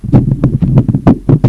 pumpkin_low.ogg